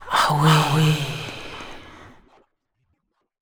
Techno / Voice / VOICEFX248_TEKNO_140_X_SC2(L).wav